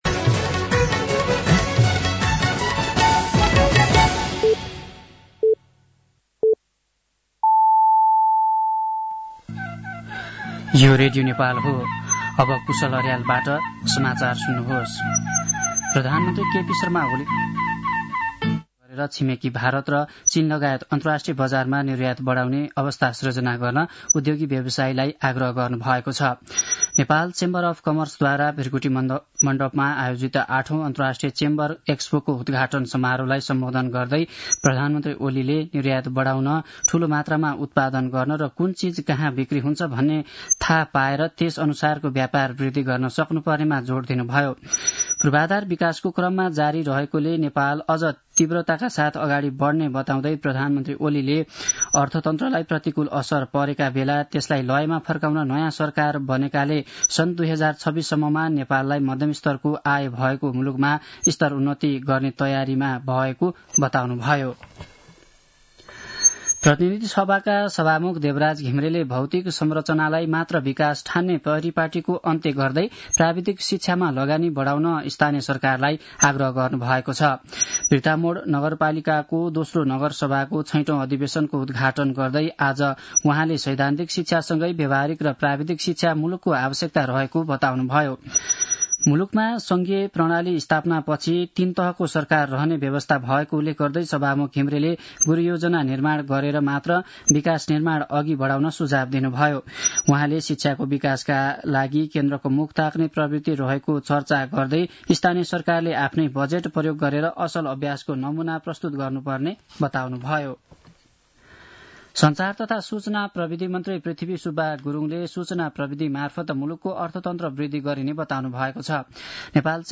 साँझ ५ बजेको नेपाली समाचार : १८ माघ , २०८१
5-pm-news-10-17.mp3